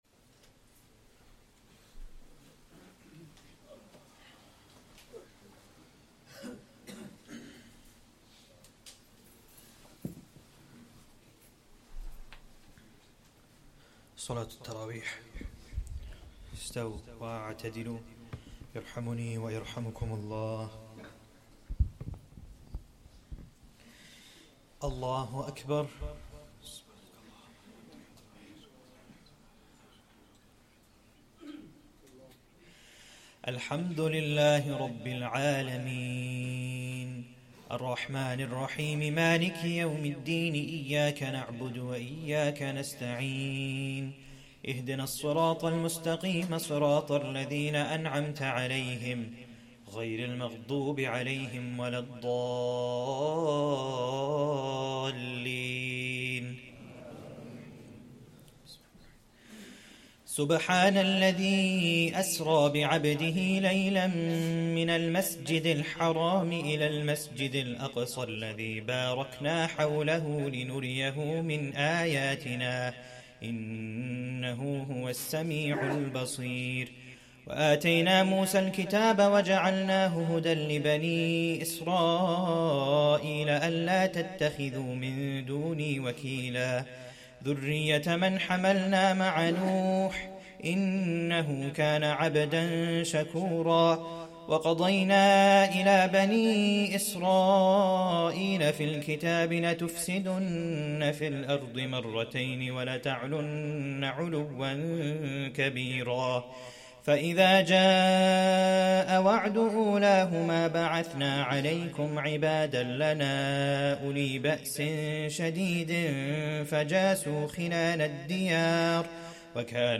1st Tarawih prayer - 13th Ramadan 2024